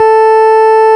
Index of /90_sSampleCDs/Keyboards of The 60's and 70's - CD1/ORG_FarfisaCombo/ORG_FarfisaCombo
ORG_VIP Pwr2 A_4.wav